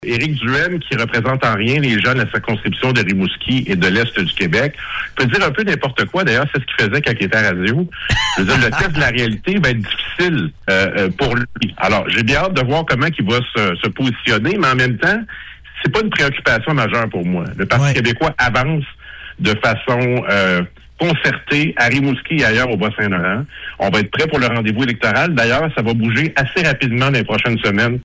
Invité à son tour sur nos ondes, monsieur Bérubé ne s’est pas gêné pour inviter Éric Duhaime à demeurer dans les grands centres et à laisser le soin à d’autres de s’occuper des régions.